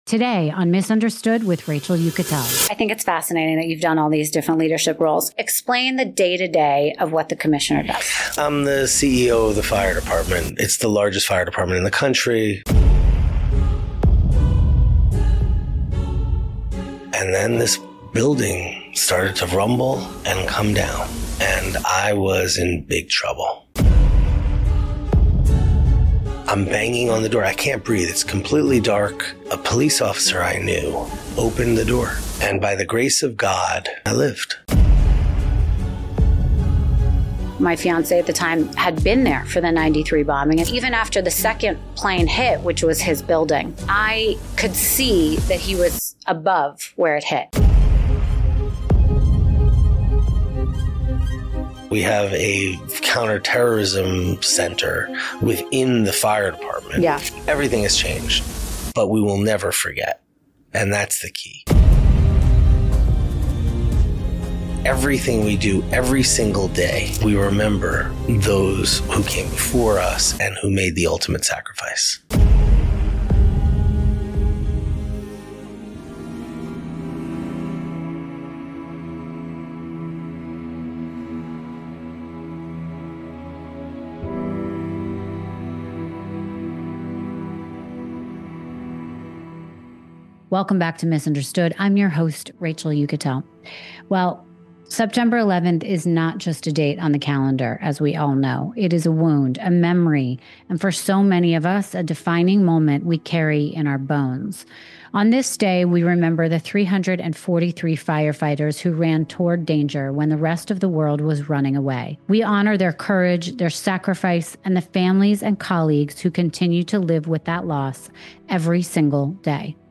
In this special episode of Miss Understood, I sit down with FDNY Commissioner Robert S. Tucker, the 35th Fire Commissioner of New York City.